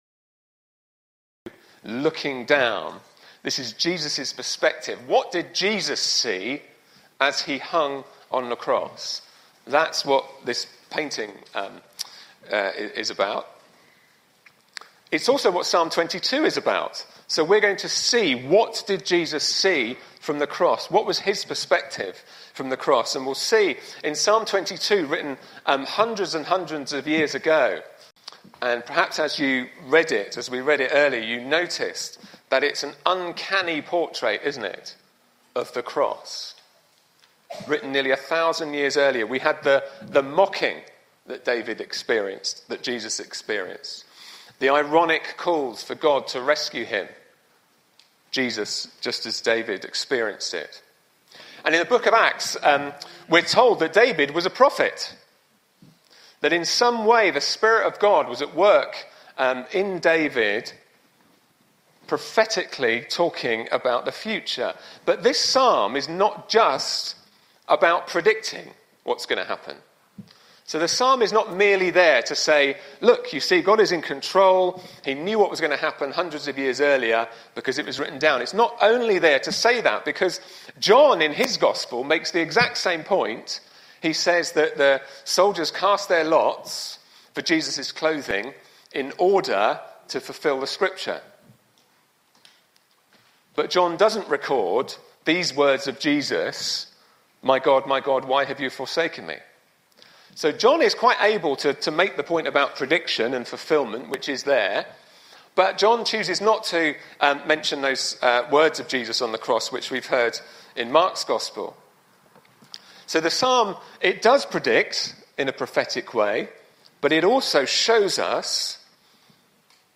Please note that there were some sound recording issues on the day, so some of the audio at the start is missing.